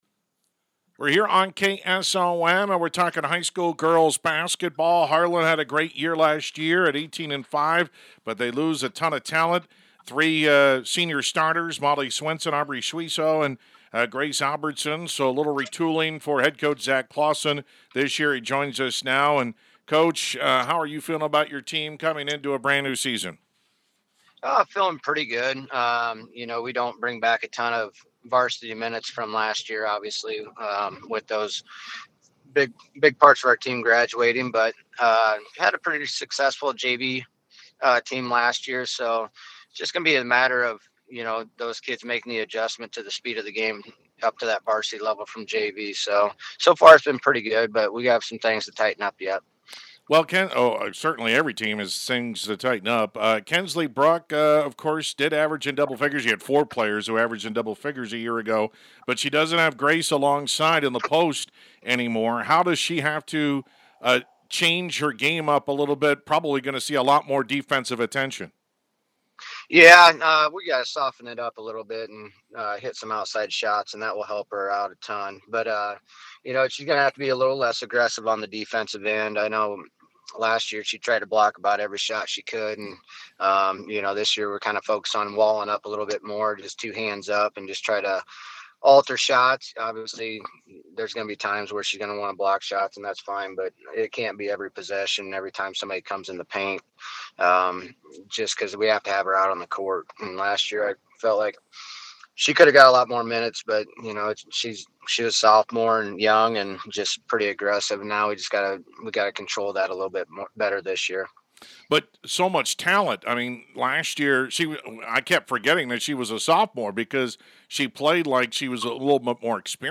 Complete Interview
harlan-girls-basketball-11-25.mp3